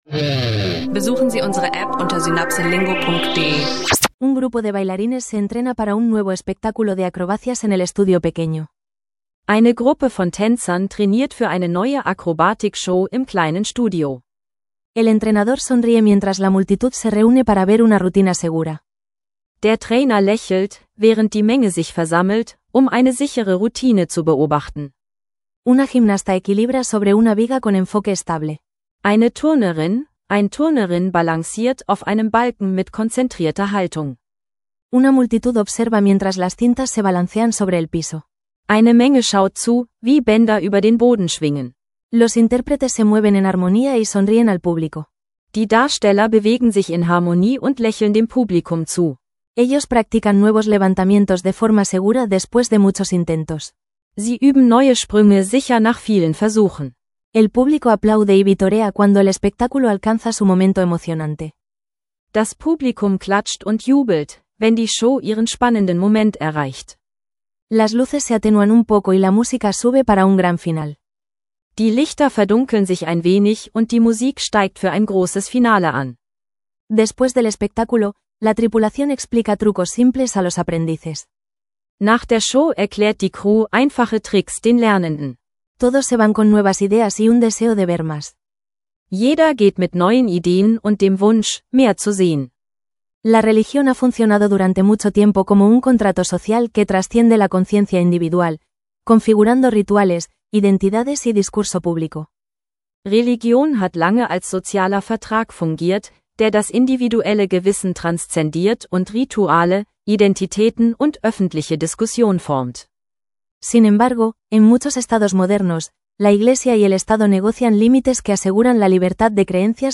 Lerne Spanisch im Alltag mit einem A2-freundlichen Gymnastik-Showcase-Dialog – inklusive Vokabeln, Sätze und Übungen.